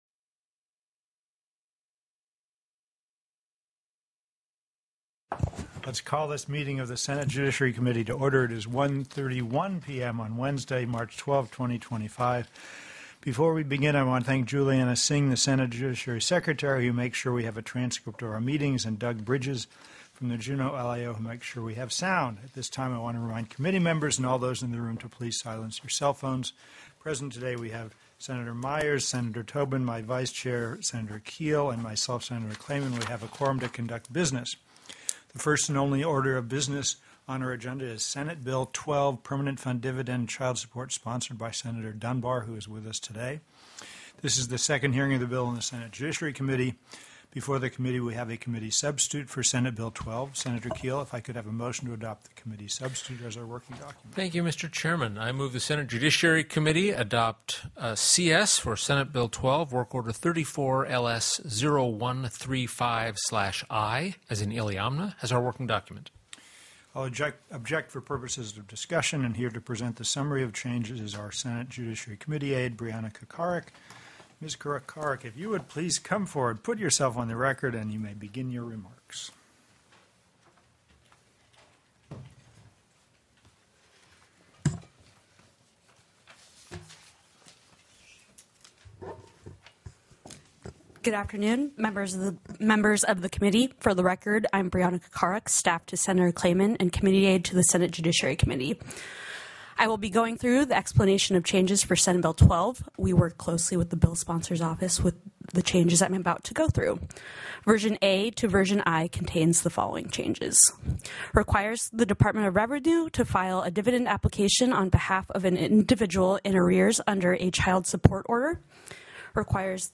+ teleconferenced
+= SB 12 PFD/CHILD SUPPORT TELECONFERENCED